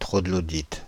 Ääntäminen
Ääntäminen France (Île-de-France): IPA: /tʁɔ.ɡlɔ.dit/ Haettu sana löytyi näillä lähdekielillä: ranska Käännös 1. koopaelanik Suku: m .